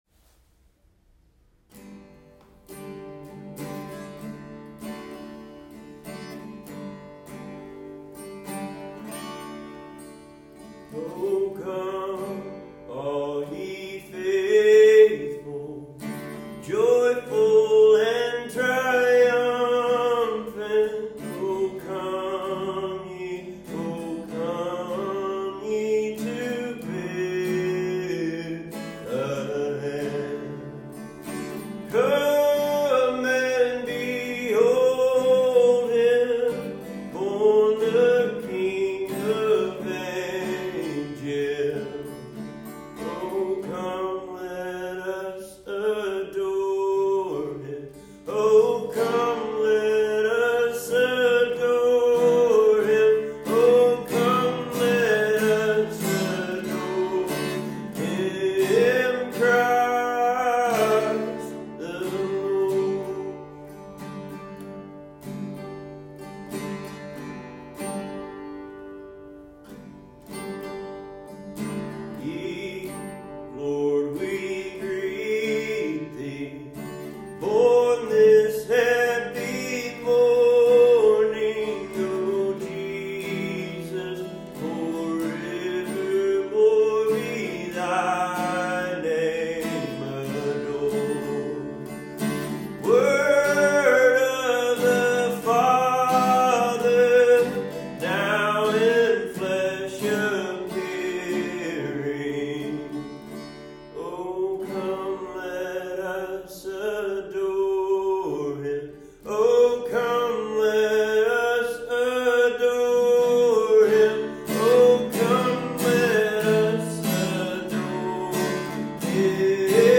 These music sessions were recorded in the Chapel on the Dunes.